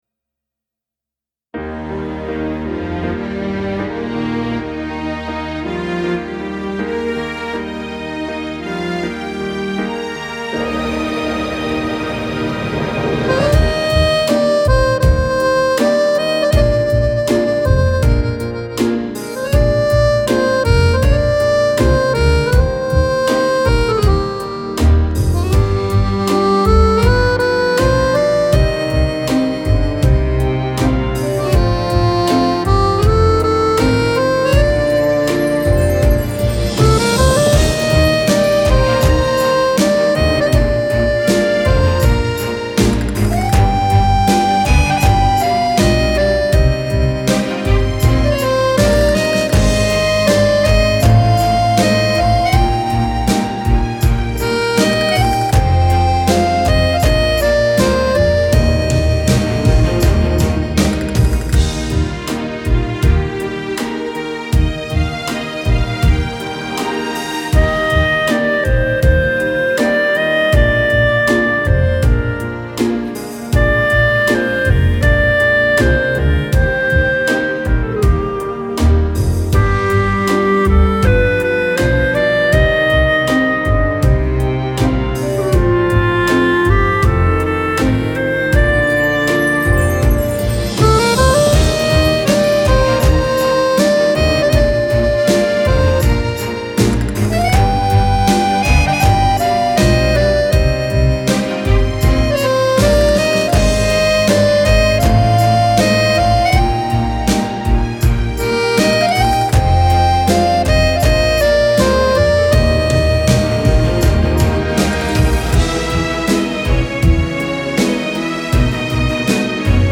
19 ballabili per Fisarmonica